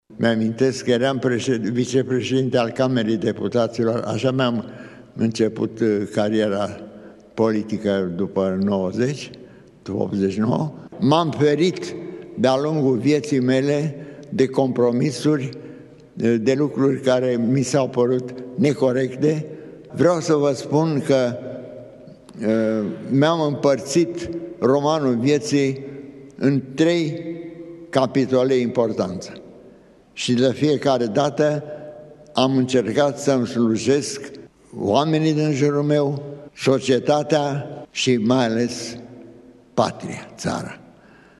Ceremonia de la Palatul Cotroceni s-a încheiat în urmă cu puțin timp.
Extrem de emoționat, Quintus a mărturisit că s-a ferit toată viața de compromisuri: